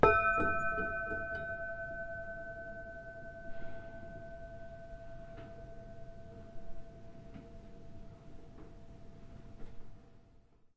奥兰 " 船铃 2
描述：在ÅlandMarithamof Mariehamn海上博物馆用手（＃1至＃8）击中不同的船铃。
Tag: 叮当 现场记录